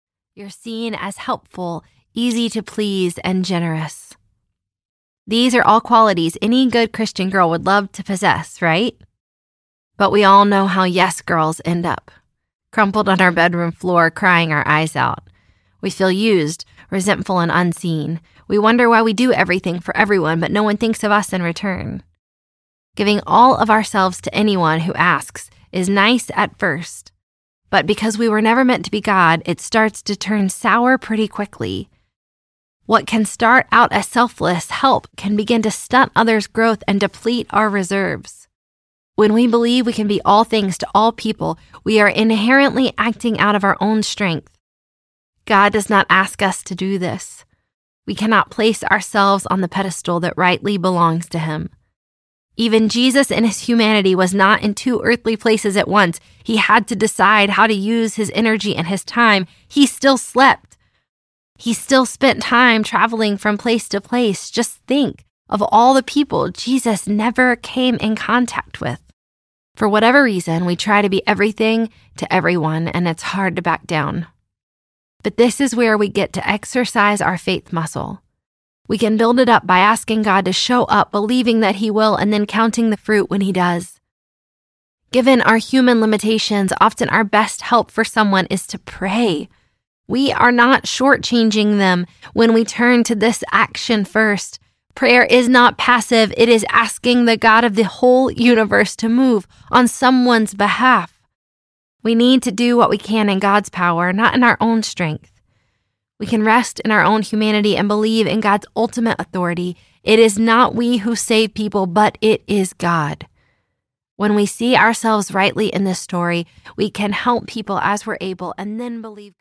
Always Enough, Never Too Much Audiobook
5.15 Hrs. – Unabridged